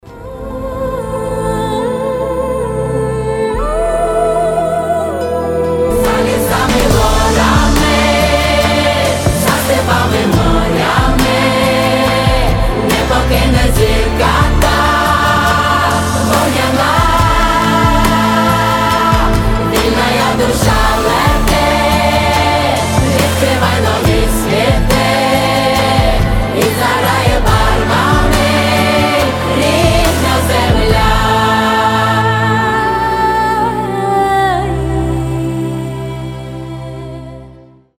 • Качество: 320, Stereo
фолк
хор
патриотические